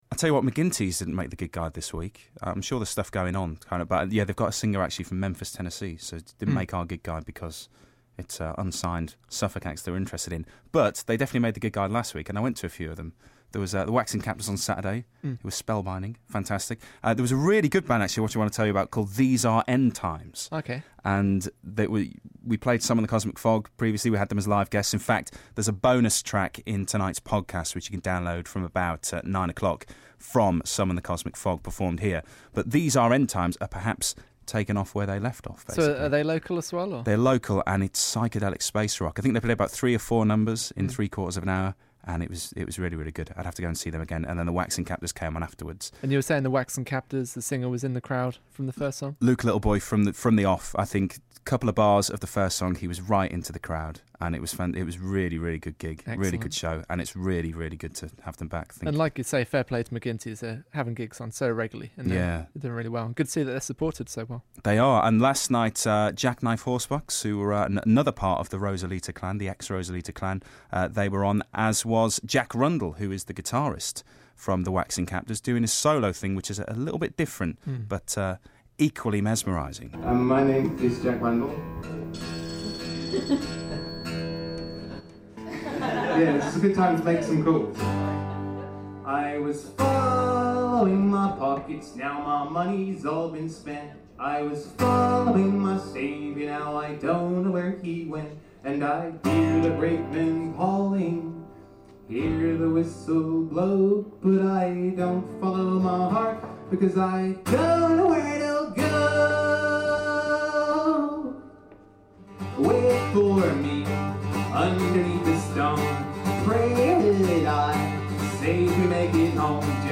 at PJ McGinty's 2009